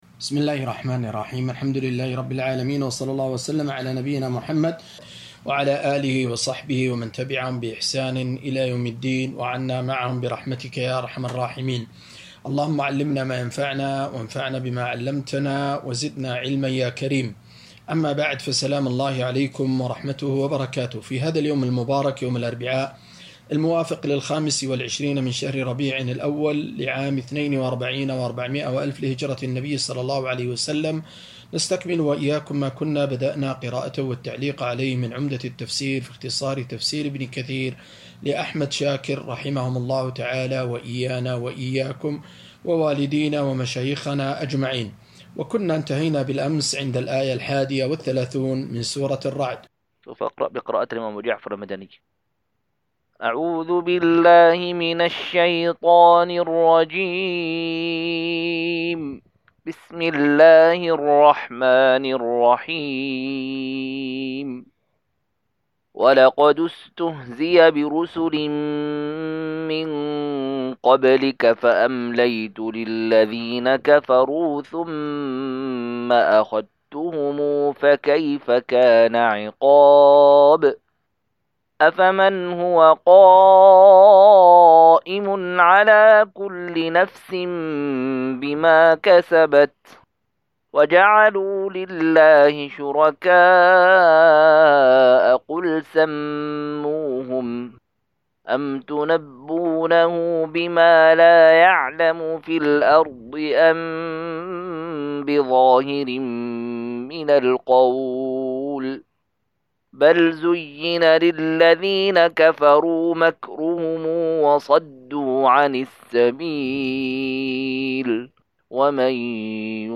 239- عمدة التفسير عن الحافظ ابن كثير رحمه الله للعلامة أحمد شاكر رحمه الله – قراءة وتعليق –